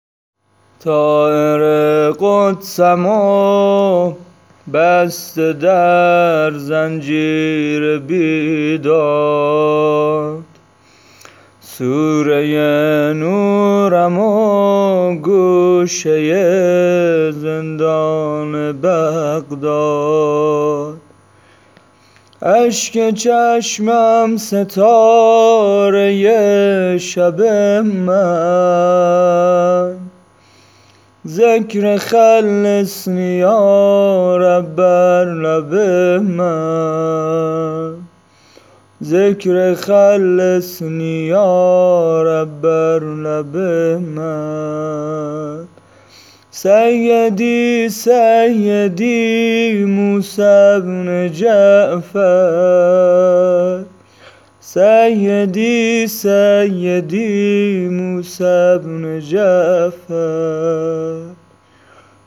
نوحه شهادت امام موسی بن جعفر, نوحه امام کاظم